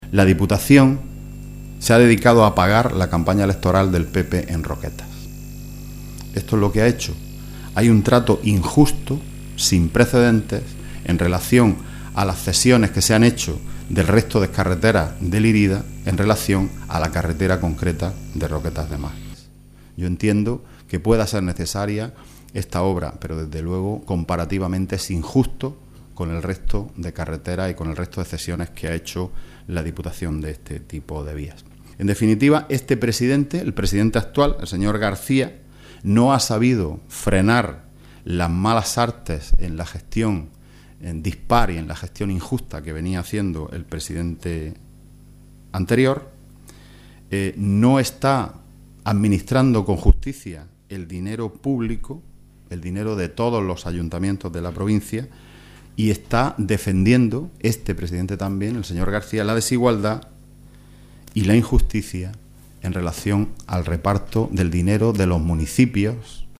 Teresa Piqueras y Juan Antonio Lorenzo durante rueda de prensa